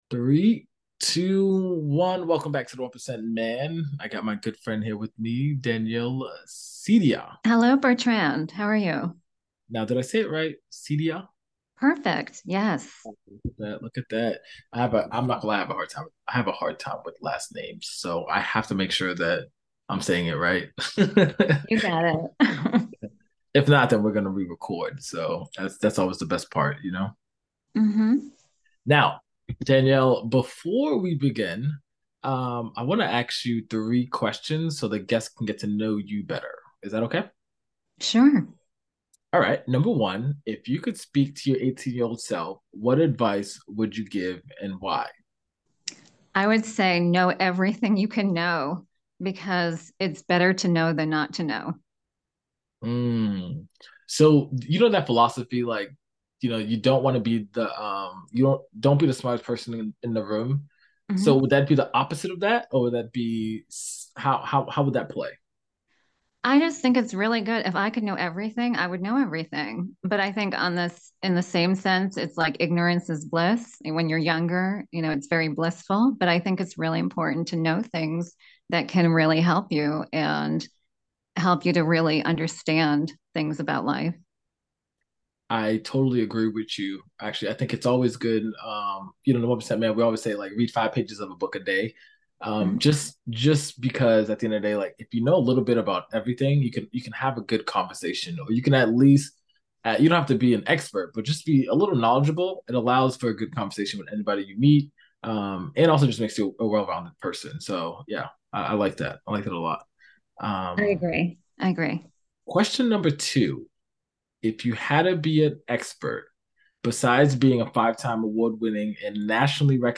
Their conversation cuts through the noise, focusing on the crucial role of honesty and directness in forging meaningful relationships.